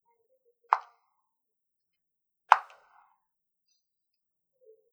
Arrugando papeles
Grabación sonora en la que se capta el sonido de alguien golpeando con una paleta una pelota de ping-pong
Sonidos: Acciones humanas